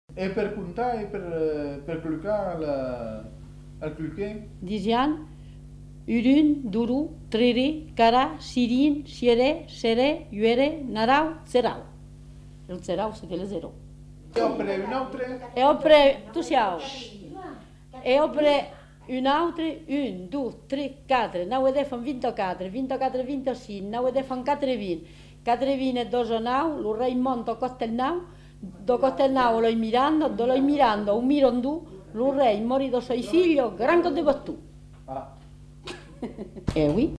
Aire culturelle : Périgord
Lieu : Castels
Genre : forme brève
Effectif : 1
Type de voix : voix de femme
Production du son : récité
Classification : comptine